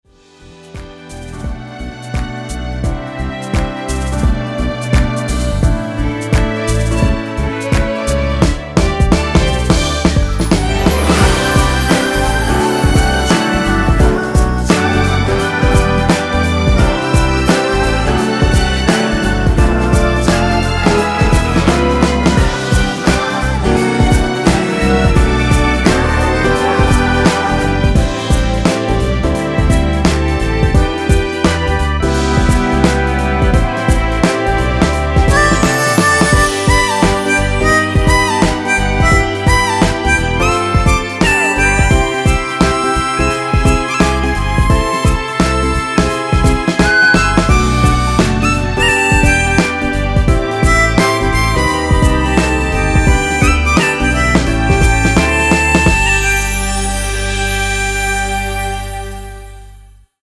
엔딩이 페이드 아웃이라서 노래하기 편하게 엔딩을 만들어 놓았으니 미리듣기 확인하여주세요!
원키에서(-1)내린 코러스 포함된 MR입니다.
앞부분30초, 뒷부분30초씩 편집해서 올려 드리고 있습니다.